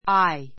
eye 小 A1 ái ア イ 名詞 複 eyes áiz ア イ ズ ❶ 目 ; 視力, 視線 blue eyes blue eyes 青い目 dark eyes dark eyes 黒い目 ⦣ a black eye は「（殴 なぐ られて）青黒くあざになった目の周り」.